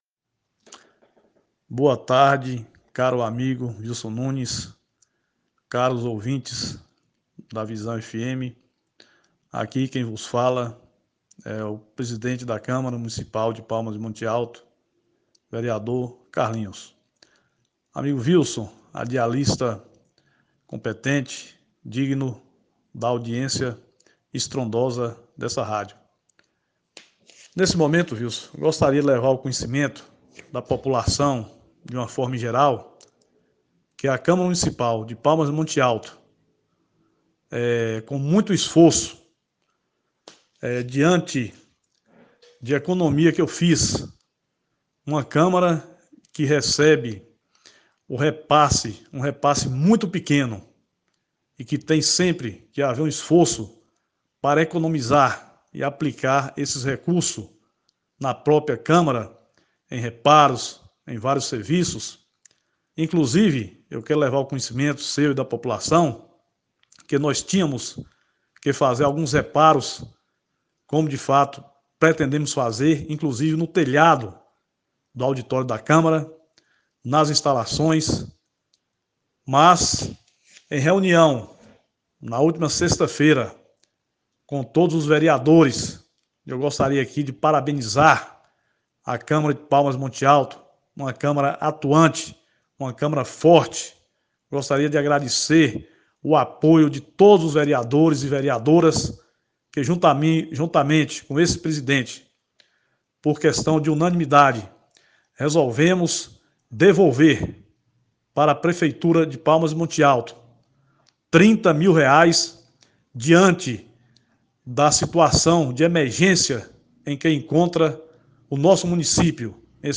CARLINHOS-VEREADOR.ogg